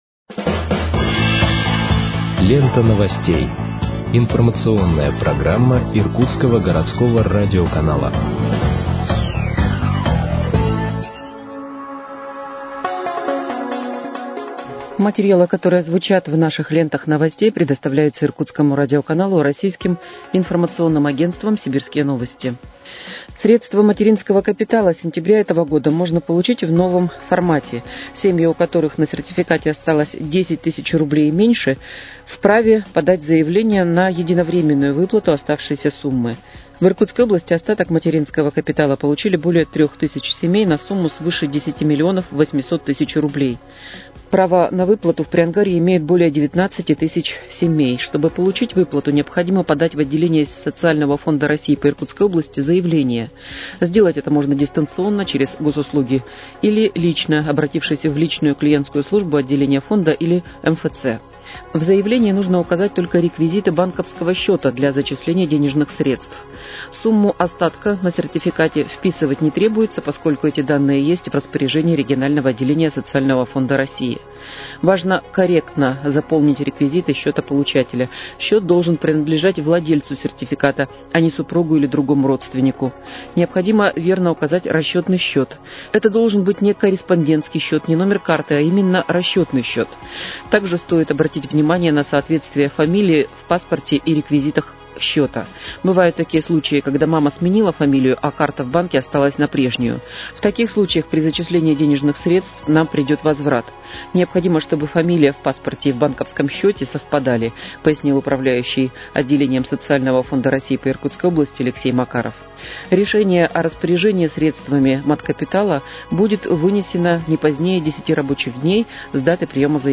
Выпуск новостей в подкастах газеты «Иркутск» от 10.10.2024 № 1